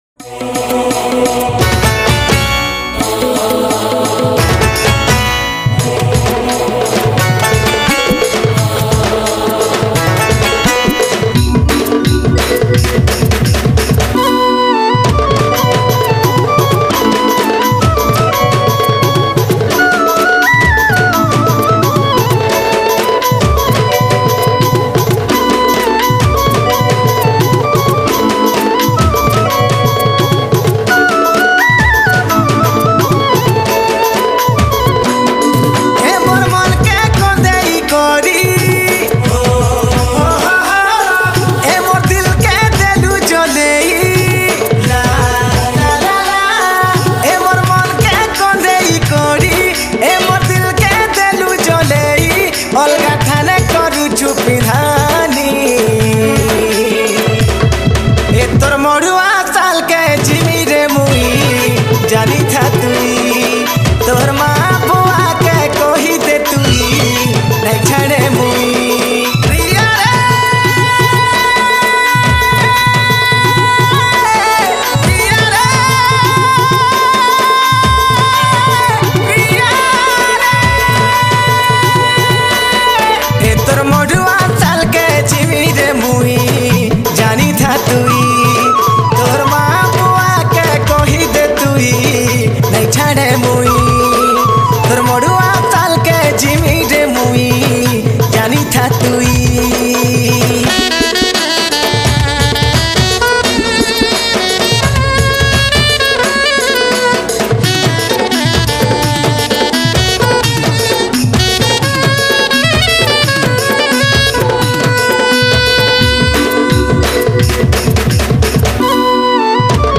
Sambalpuri Songs